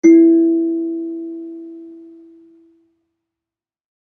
kalimba1_circleskin-E3-mf.wav